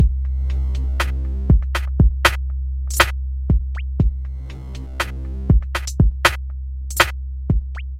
洛菲节拍120bpm
描述：lofi beat 120bpm
Tag: 120 bpm Hip Hop Loops Drum Loops 1.35 MB wav Key : C